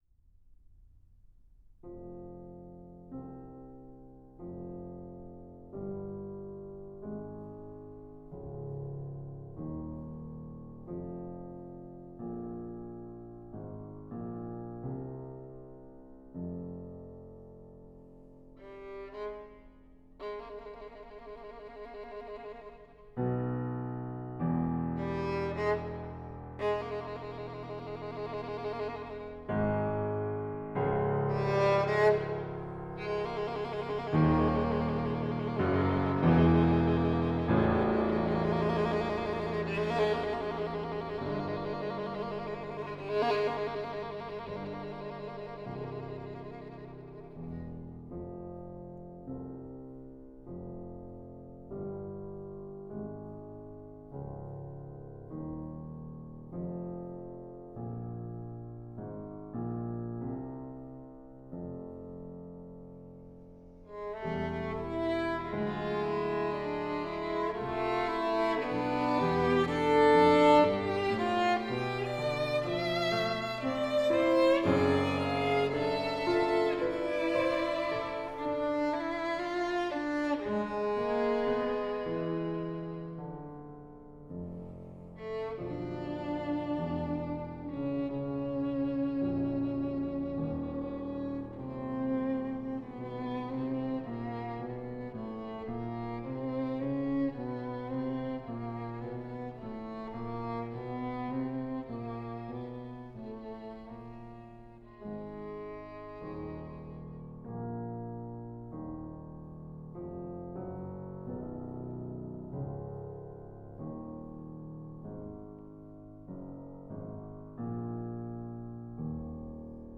Piano
Violin